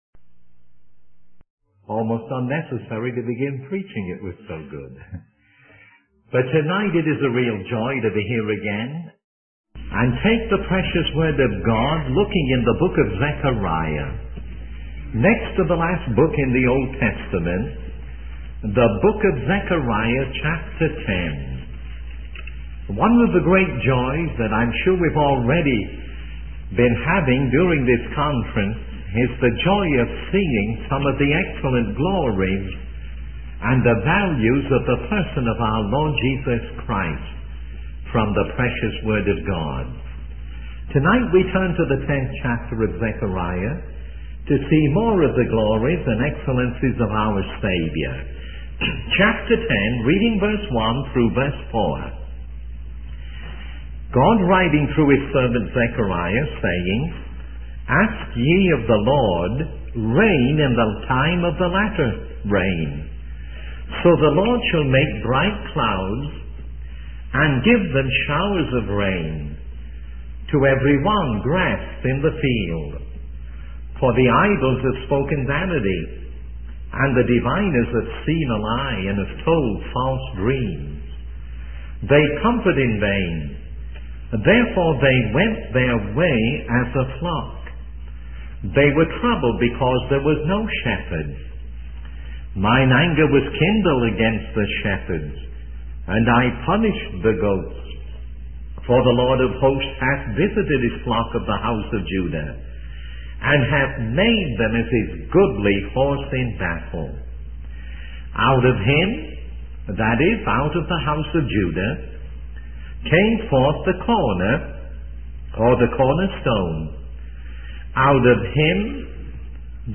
In this sermon, the preacher discusses four names that are significant in understanding the nature of God.